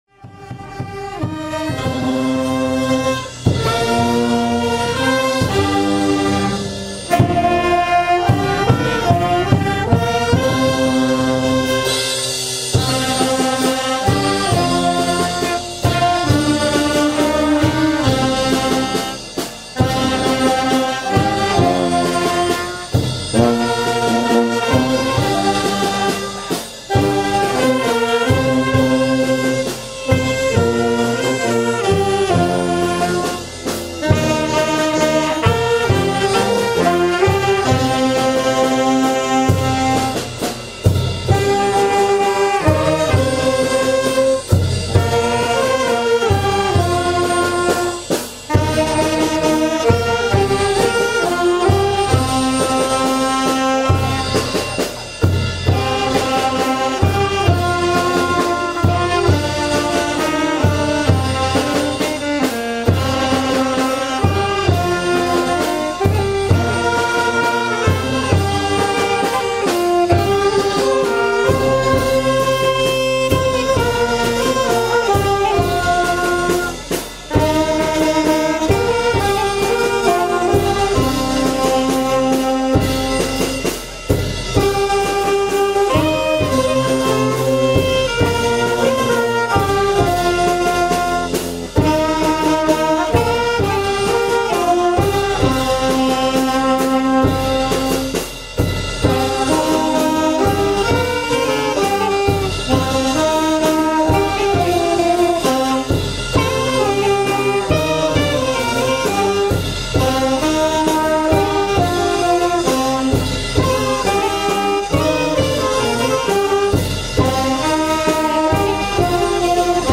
sâu lắng, cảm động
bản nhạc không lời